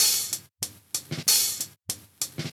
Closed Hats
HATLOOP.wav